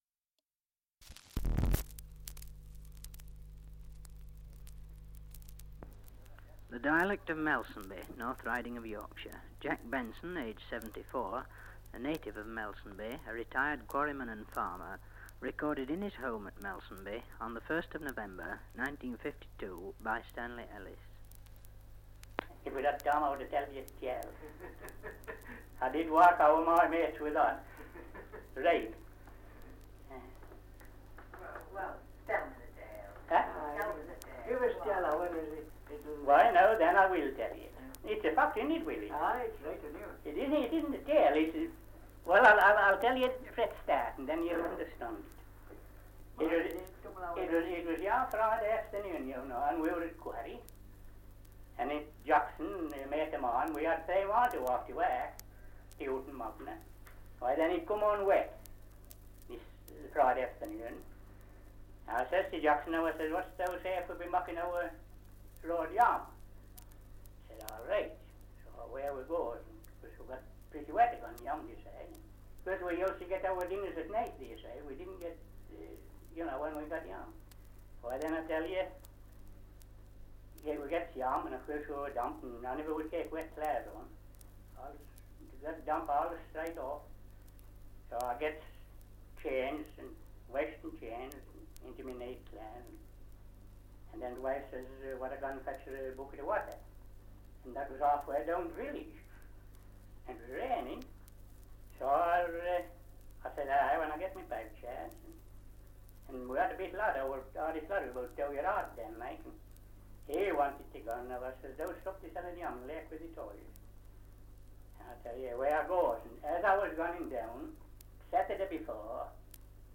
1 - Survey of English Dialects recording in Melsonby, Yorkshire
78 r.p.m., cellulose nitrate on aluminium